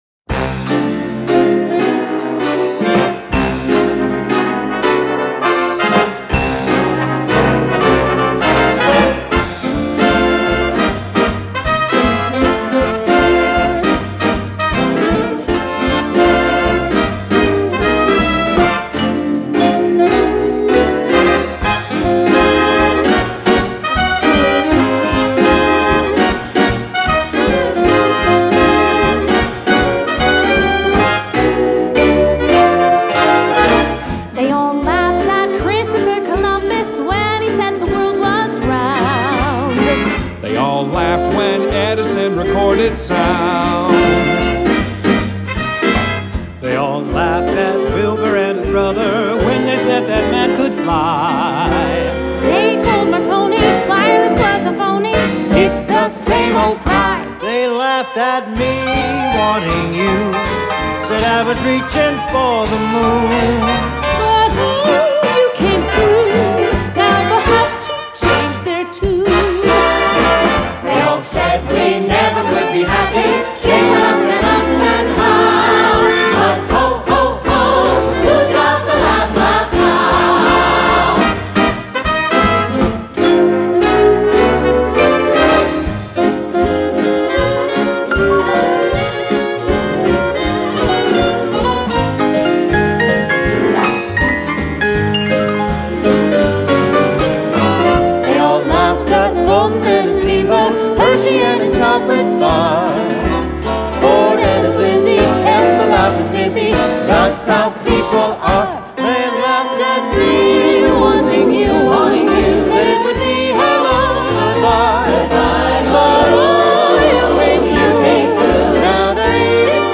the show’s cast sings it after the bows